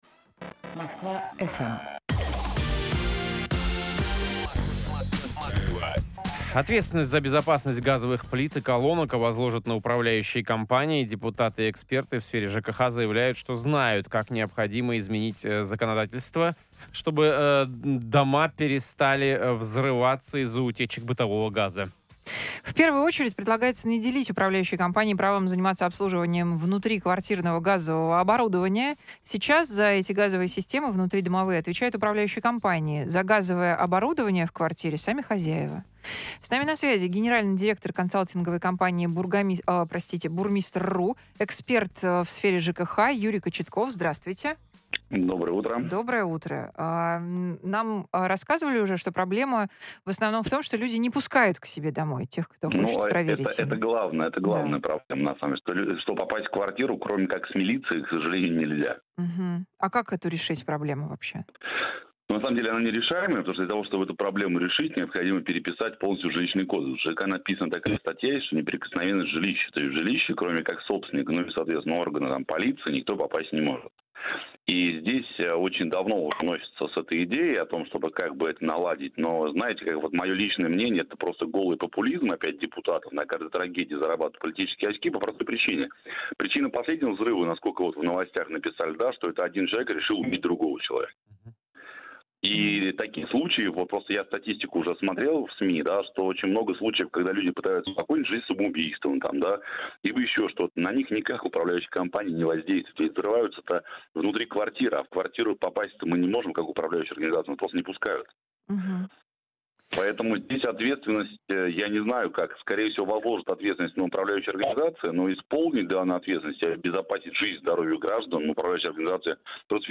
в прямом эфире на Москва-FM